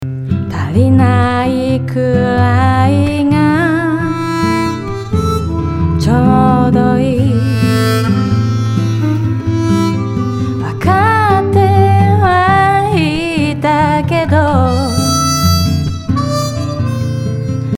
にしてリバーブ音のEQのハイを下げてみて温かみを演出してみました。
部屋の壁に反射している雰囲気も落ち着いた感じになっている気がします。